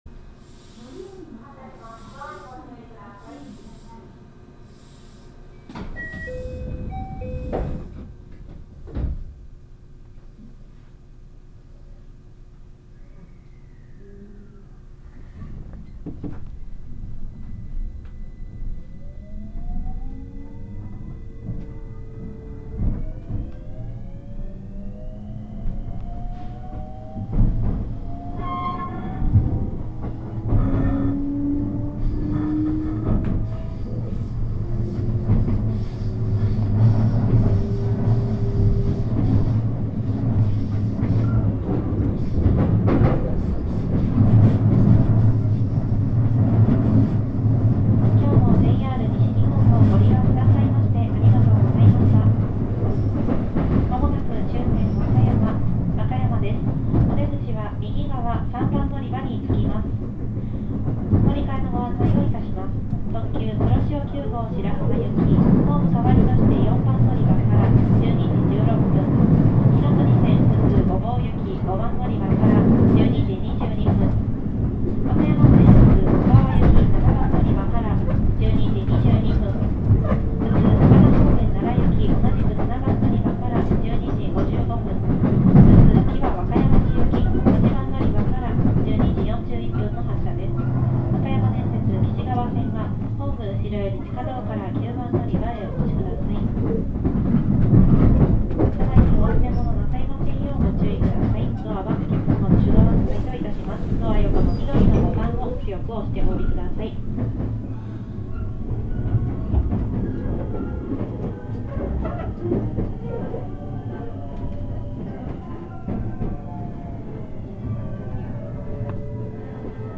走行音
録音区間：紀伊中ノ島～和歌山(紀州路快速)(お持ち帰り)